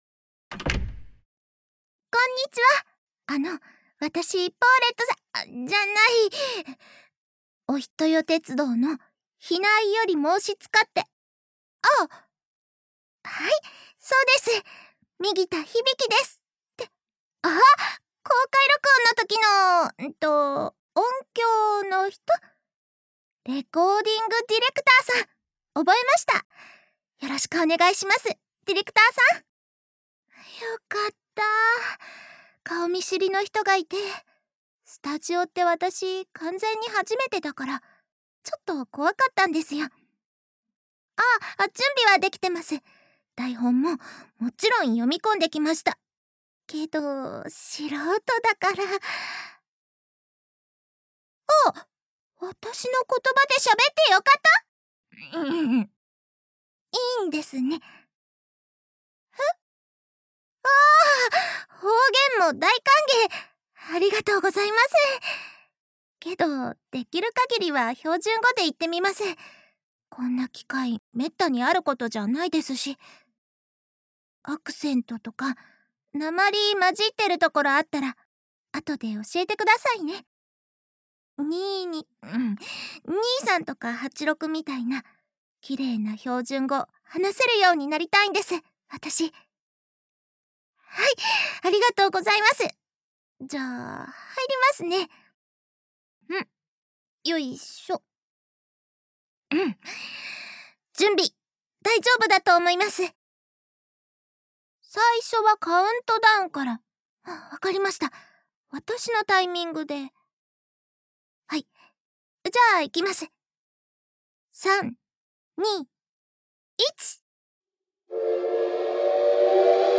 4月7日配信 第19回 パーソナリティ：日々姫